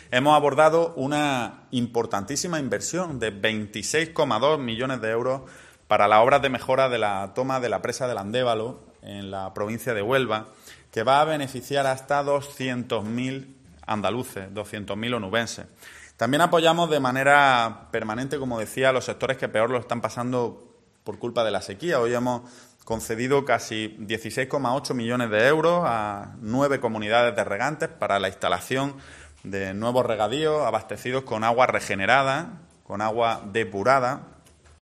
Así lo ha comunicado el portavoz del Gobierno andaluz, Ramón Fernández-Pacheco, al término del Consejo de Gobierno celebrado ayer en Sevilla.